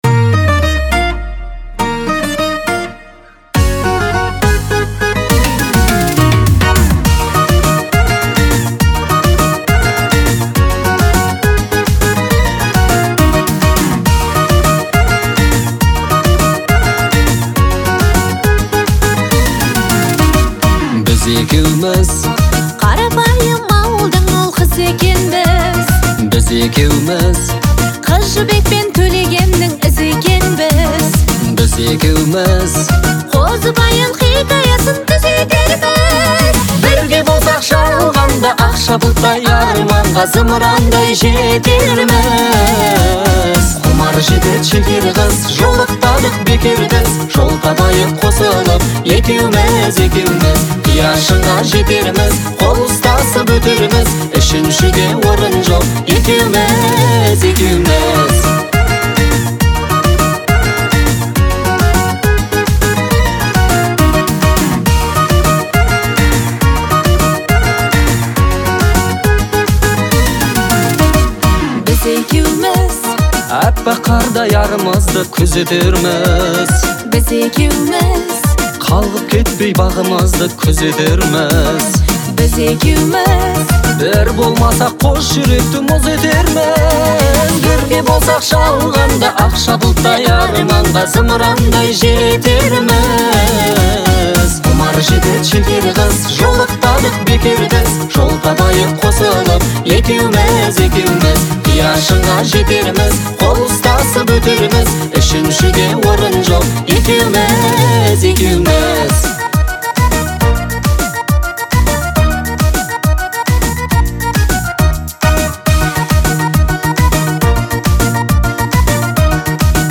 дуэта